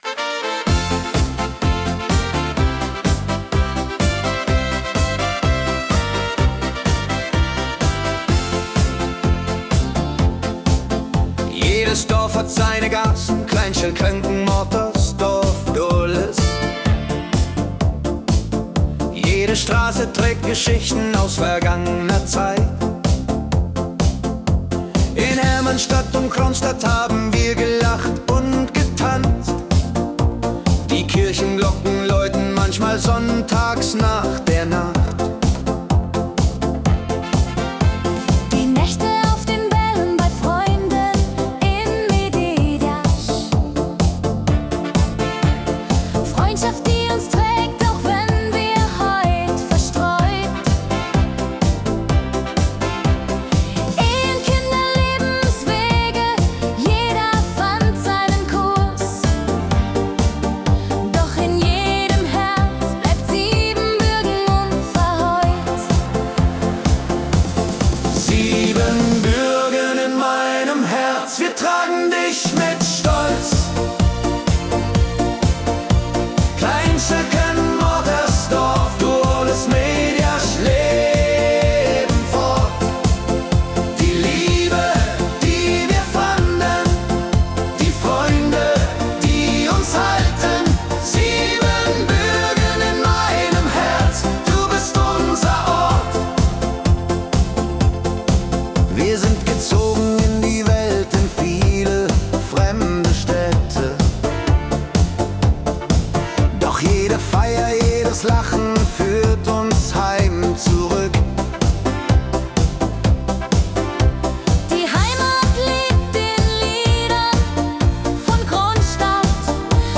Volks-Hit
• KI-generierte Melodie